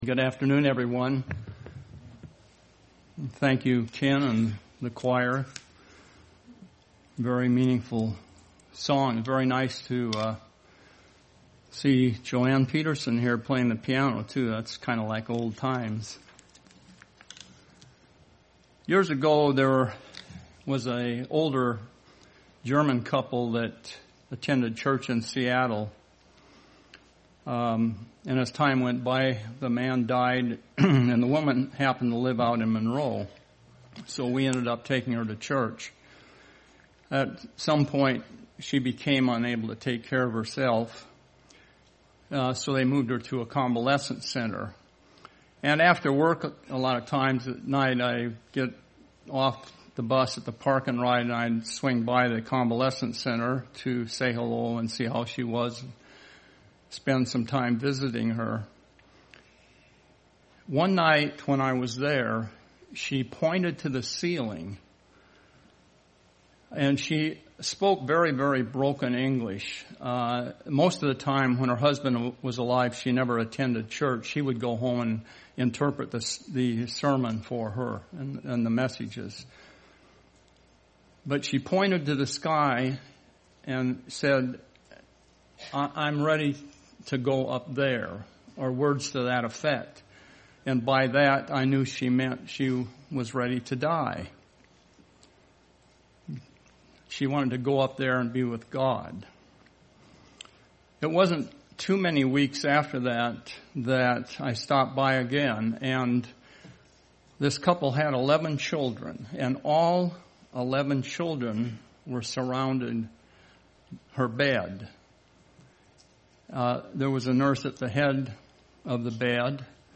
Given in Seattle, WA
Print UCG Sermon